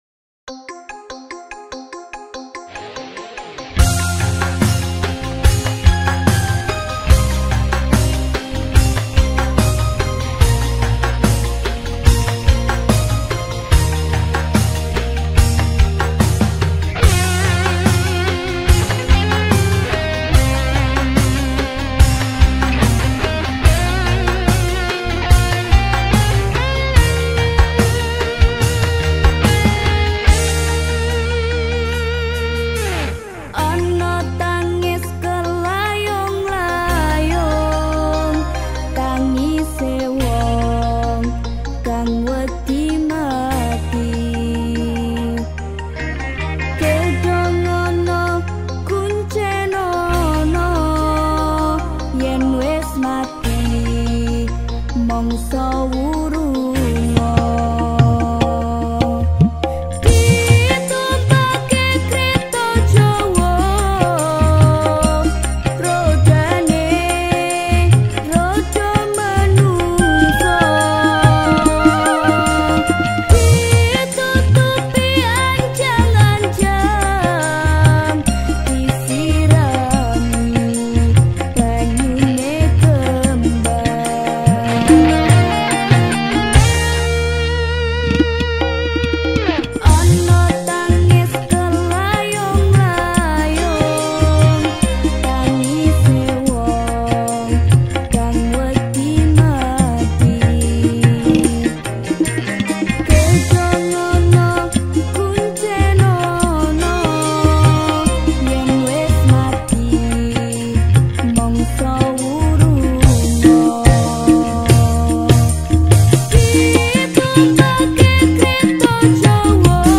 lagu dangdut koplo yang dibawakan oleh para wanita cantik